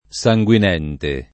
SajgUin$nte] agg. — ant. o poet. per «sanguinante, sanguinoso»: per le rotture sanguinenti [per le rott2re SajgUin$nti] (Dante); al mio cor sanguinente [al mio k0r SajgUin$nte] (Foscolo)